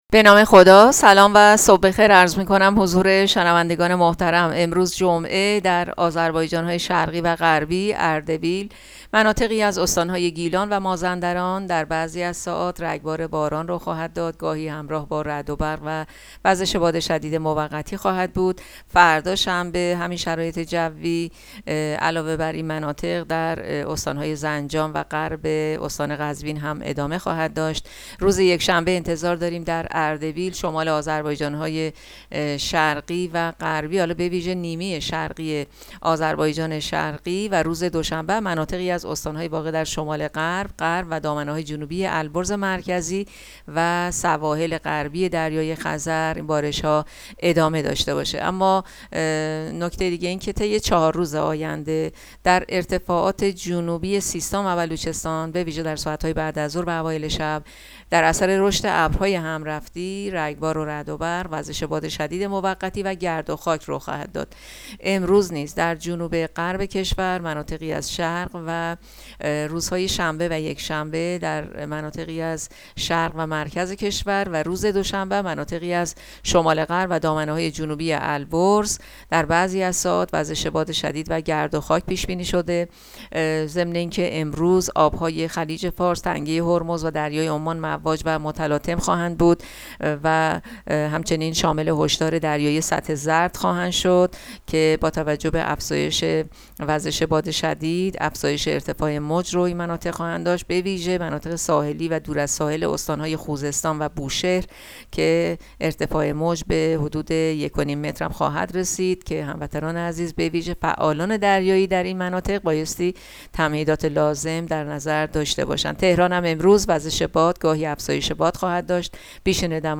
گزارش رادیو اینترنتی پایگاه‌ خبری از آخرین وضعیت آب‌وهوای نوزدهم اردیبهشت؛